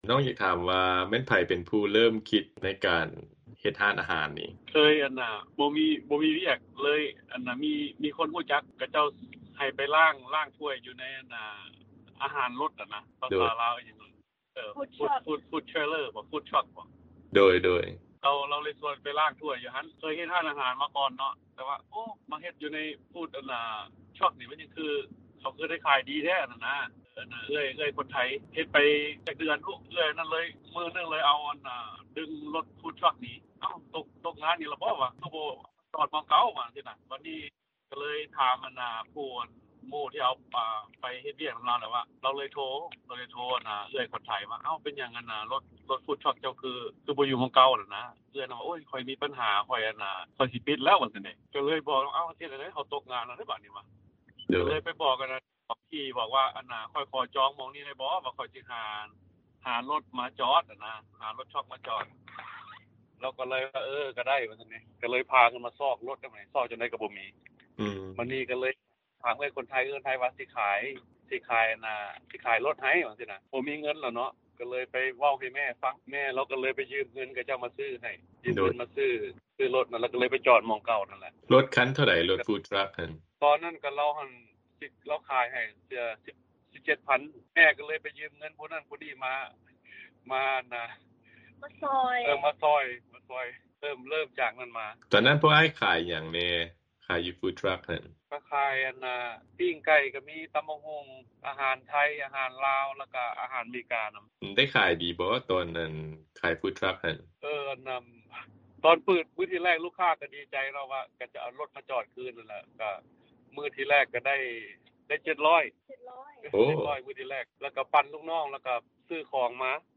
ພວກເຮົາໄດ້ສຳພາດກັບທັງສອງຄົນ ກ່ຽວກັບ ການດຳລົງຊີວິດໃນລັດດັ່ງກ່າວ ແລະ ຮ້ານອາຫານຂອງເຂົາເຈົ້າ.